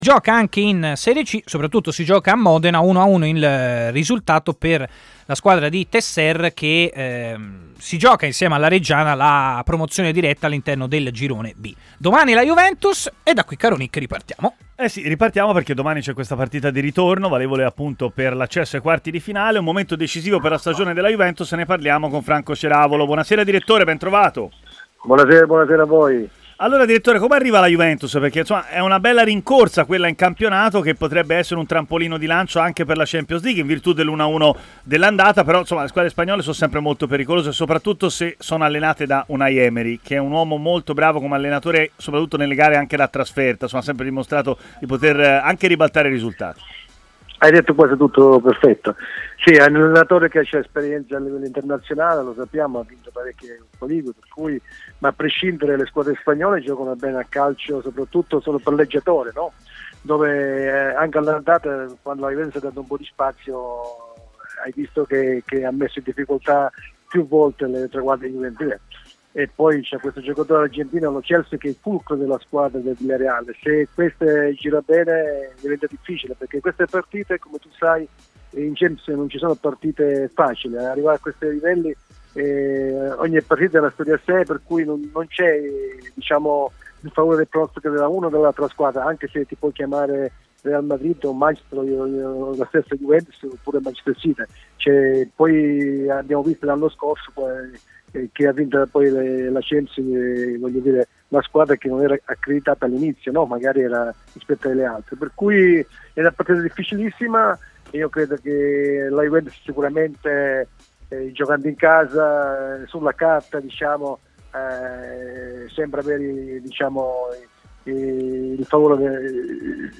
trasmissione di TMW Radio